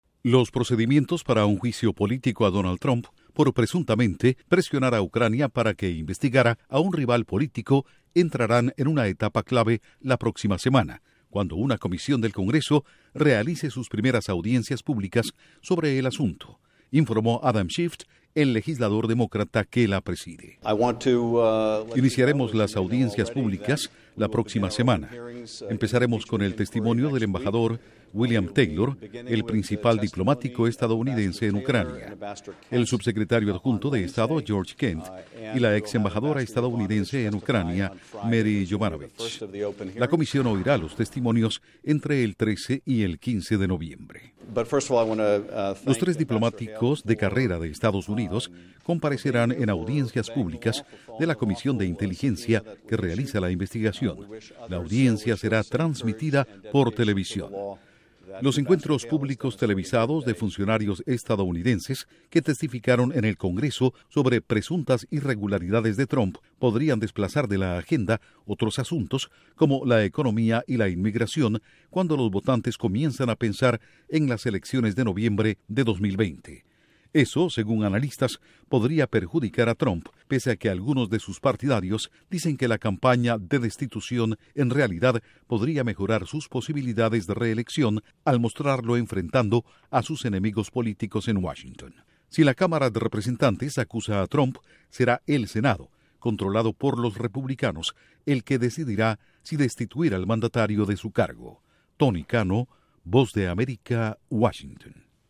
Duración: 1:45 Con declaraciones de Adam Schiff/Demócrata Comisión Congreso y reacciones de republicanos